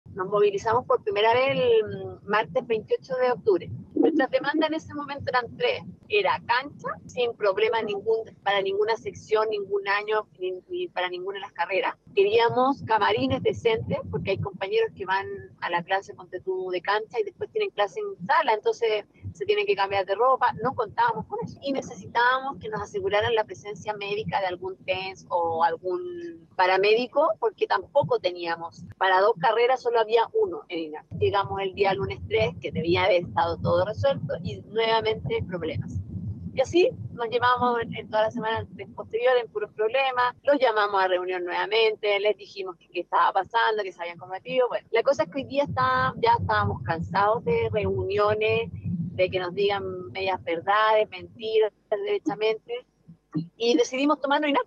ADN Deportes conversó con una alumna del Instituto Nacional de Fútbol, quien explicó las protestas que han desarrollado desde este viernes.